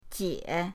jie3.mp3